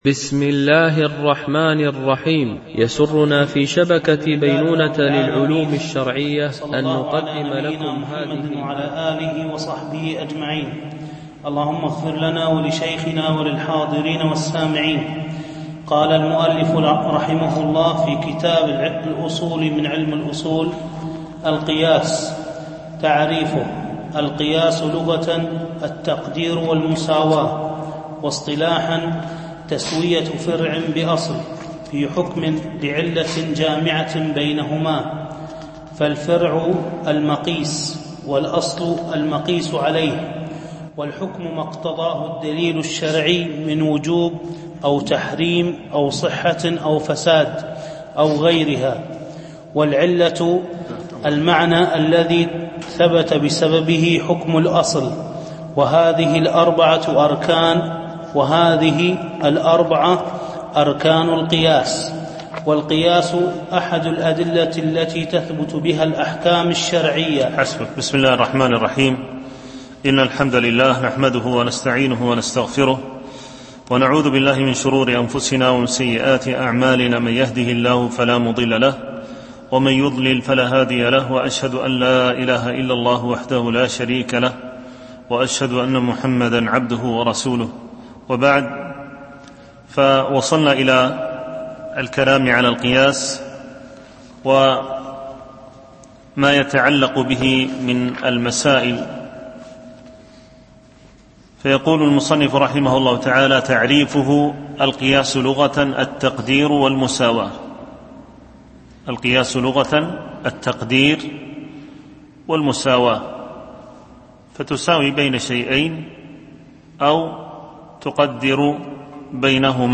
شرح الأصول من علم الأصول ـ الدرس 23 (القياس الجزء الأول)